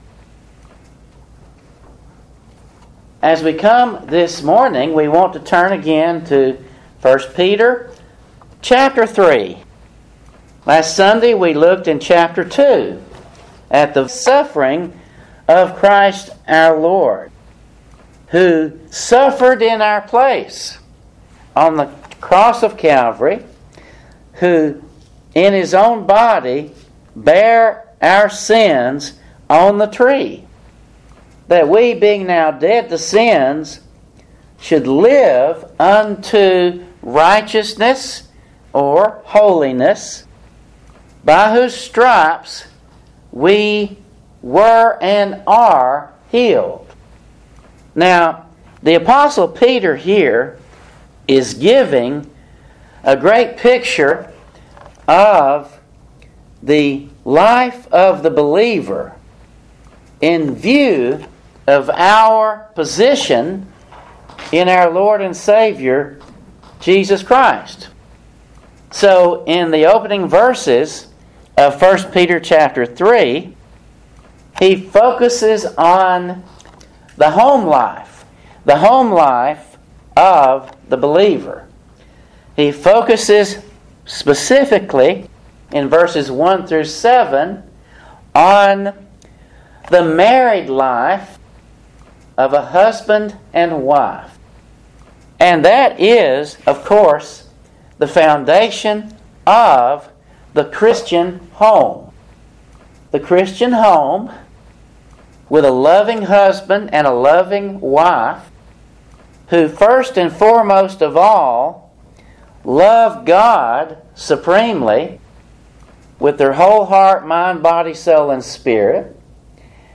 Today's Sermon